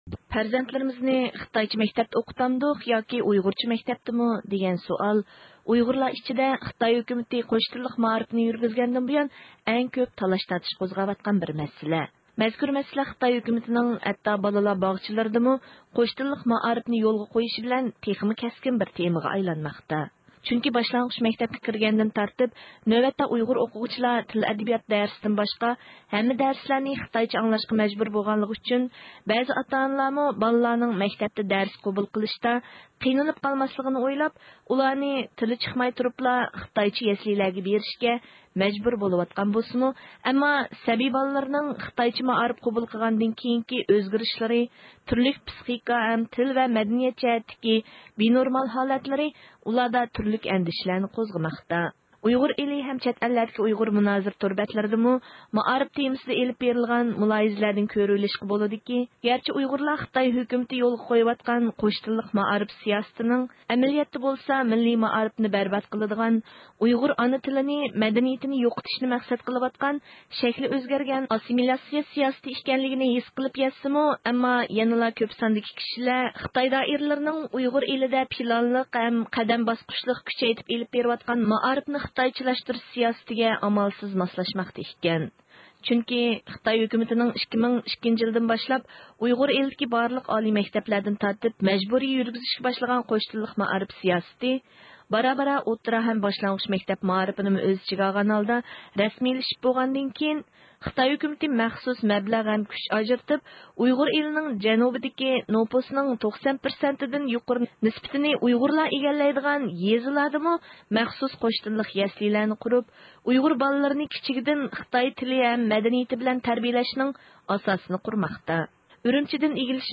يېقىندا ئۇيغۇر ئېلىنىڭ مەلۇم يېزىلىق تولۇقسىز ئوتتۇرا مەكتەپتىن خىتاي تىلىدا دەرس ئۆتەلمىدىڭ دېگەن باھانە بىلەن دەرس مۇنبىرىدىن قوغلانغان، ئىسمىنى ئاشكارىلاشنى خالىمىغان بىر ئوقۇتقۇچى بىلەن قوش تىللىق مائارىپنىڭ يېزا مەكتەپ ھەتتا بالىلار باغچىلىرىغىچە قانداق يوسۇندا كېڭەيتىپ ئېلىپ بېرىلىۋاتقانلىقى ھەققىدە سۆھبەتلەشتۇق.